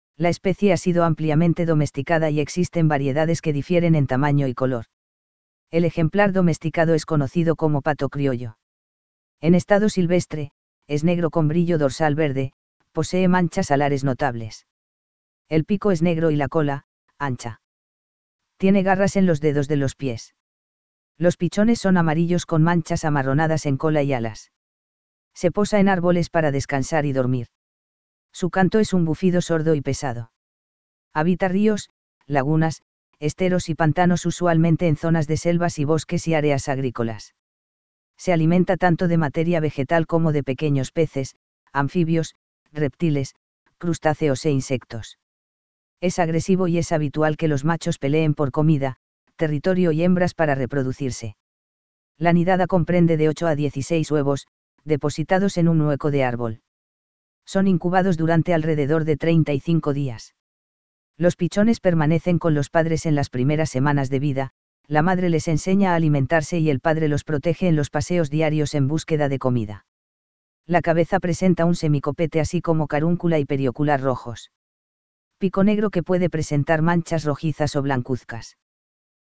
Su canto es un bufido sordo y pesado.